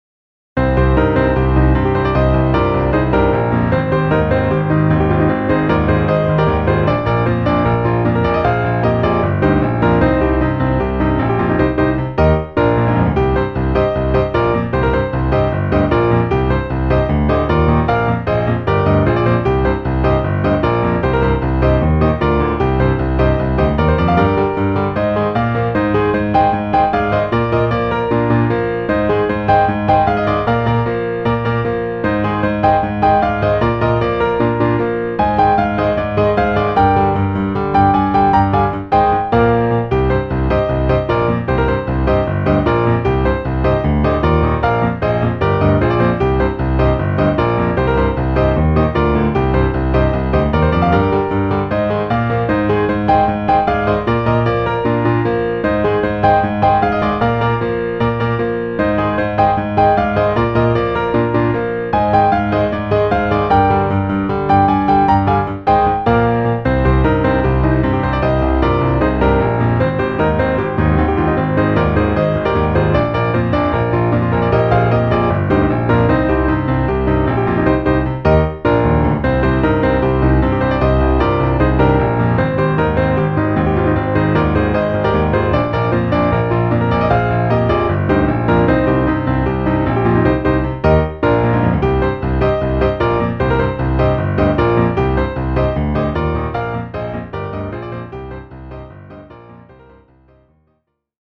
PIANO部屋 新着10曲分・一覧表示は こちら